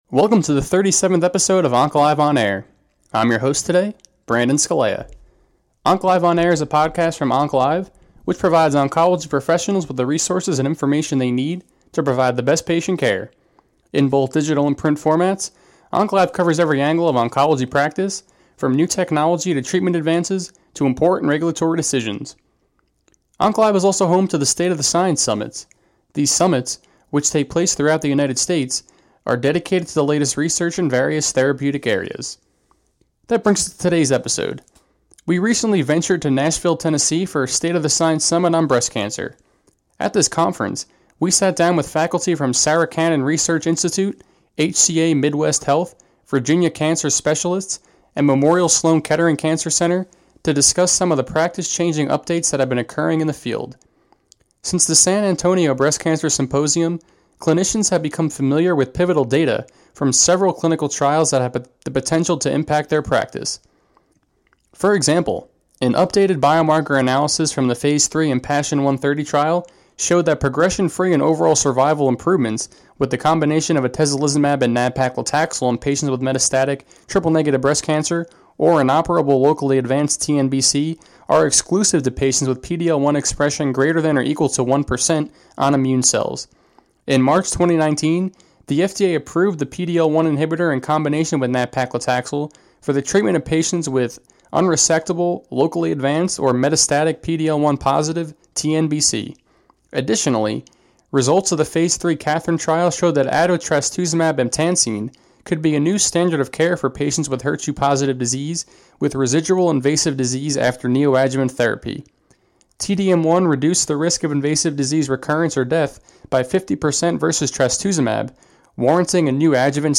Facebook Twitter Headliner Embed Embed Code See more options We recently ventured to Nashville, Tennessee for a State of the Science SummitTM on Breast Cancer. At this conference, we sat down with faculty from Sarah Cannon Research Institute, HCA Midwest Health, Virginia Cancer Specialists, and Memorial Sloan Kettering Cancer Center to discuss some of the practice-changing updates that have been occurring in the field.